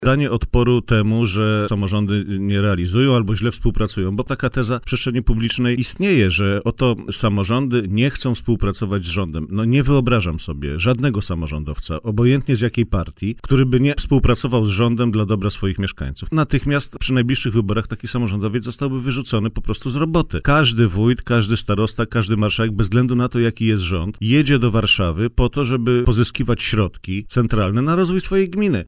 – Chcemy pokazać, że samorządy dobrze realizują program rozwoju wsi – stwierdził poseł PSL Piotr Zgorzelski w poranku „Siódma9” na antenie Radia Warszawa.